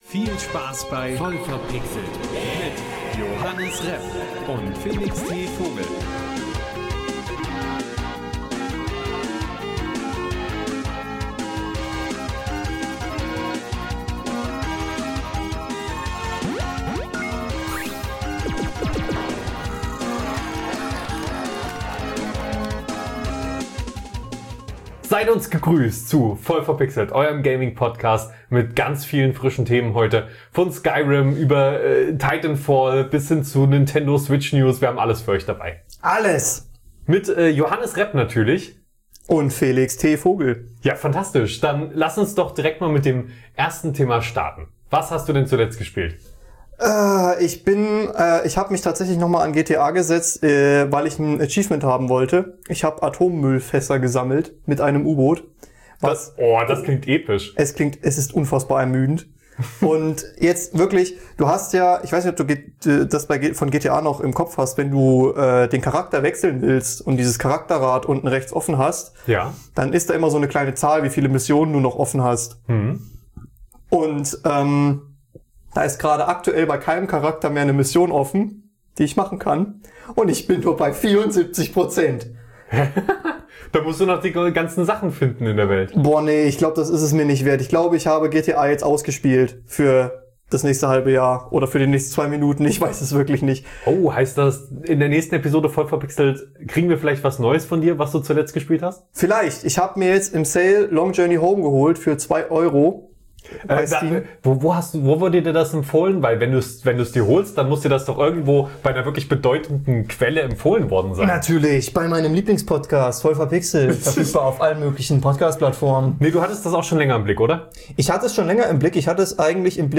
Anregende Gespräche, spaßige Geschichten und interessante Einblicke in die vielseitige Welt der Videospiele.